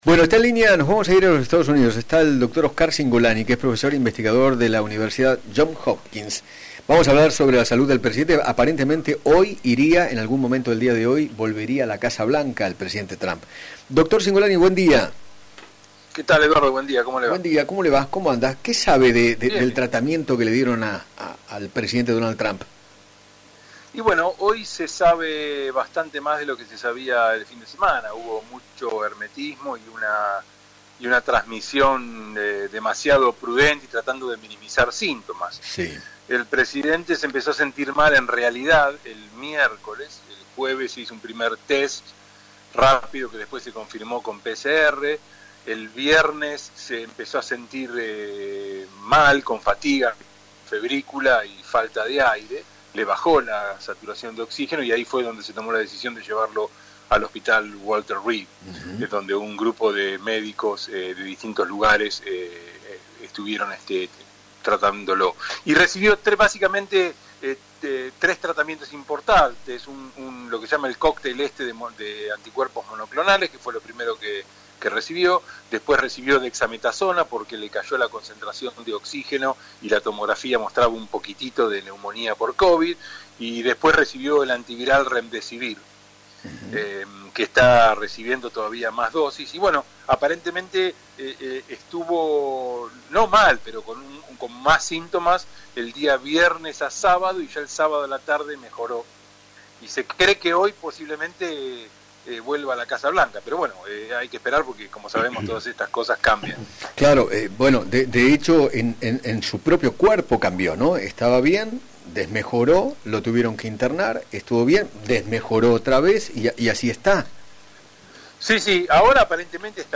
dialogó con Eduardo Feinmann sobre la salud del presidente norteamericano y explicó los tratamientos experimentales que recibió, tras dar positivo de covid el pasado viernes.